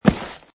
grass3.mp3